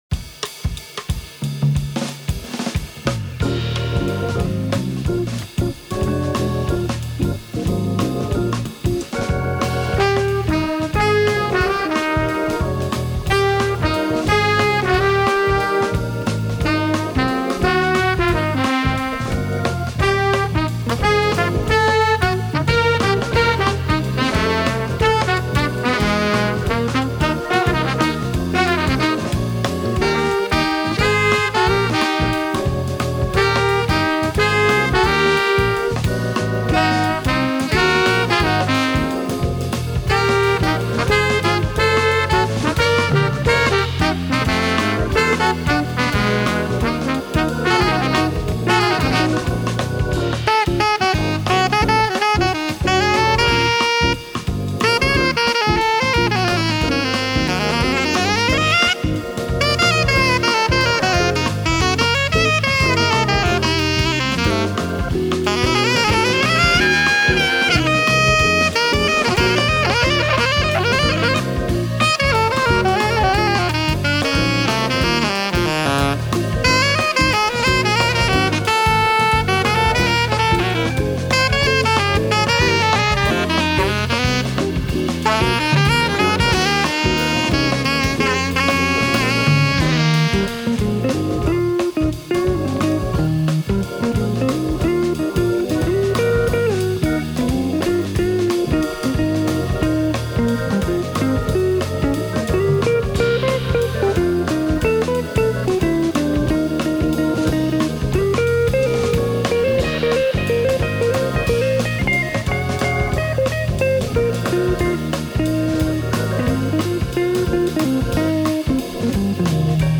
Going for Radio AddsMarch 18, 2022File: Jazz